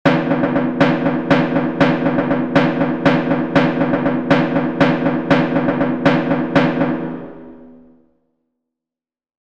• KALAMATIANOS : Danza griega en 7/8 que se toca como 3 + 2 + 2.
Audio de elaboración propia. Patrón rítmico Kalamatianos. (CC BY-NC-SA)